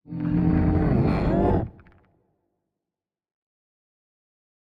Minecraft Version Minecraft Version snapshot Latest Release | Latest Snapshot snapshot / assets / minecraft / sounds / mob / warden / ambient_9.ogg Compare With Compare With Latest Release | Latest Snapshot